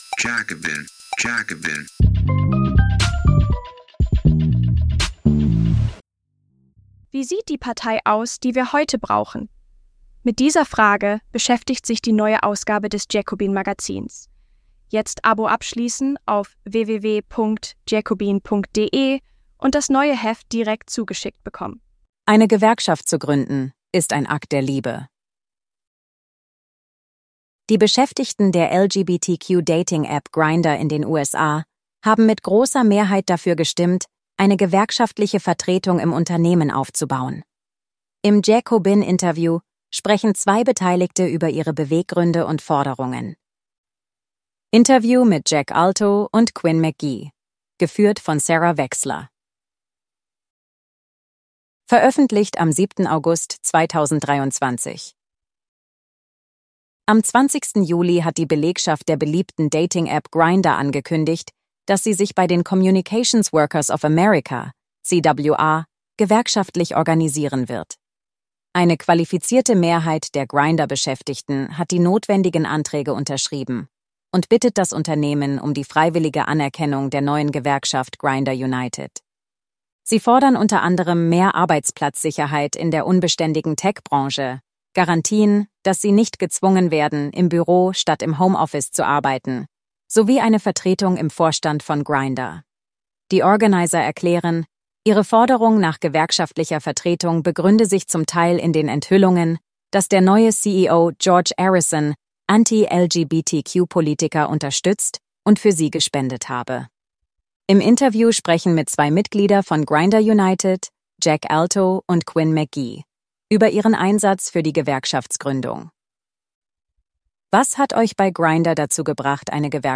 Die Beschäftigten der LGBTQ-Dating-App Grindr in den USA haben mit großer Mehrheit dafür gestimmt, eine gewerkschaftliche Vertretung im Unternehmen aufzubauen. Im JACOBIN-Interview sprechen zwei Beteiligte über ihre Beweggründe und Forderungen.